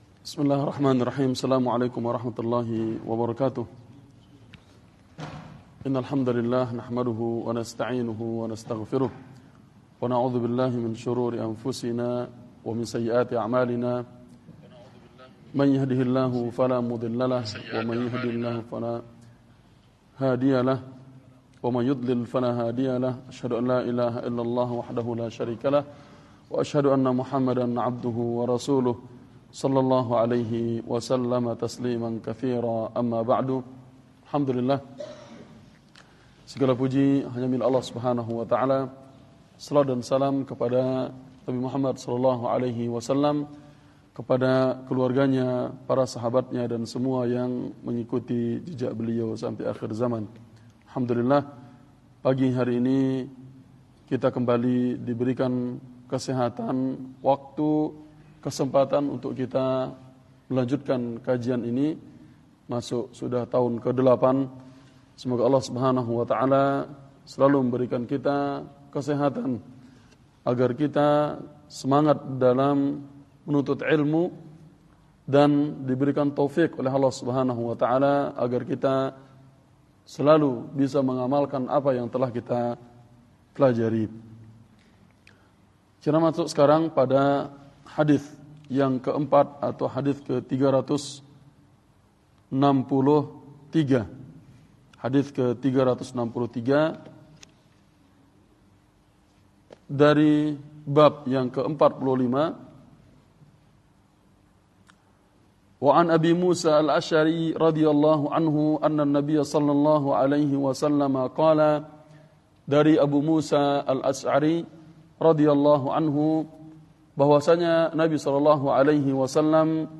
Daurah Al-Khor Sabtu Pagi – Masjid At-Tauhid Syarah Riyadhus Shalihin Bab 45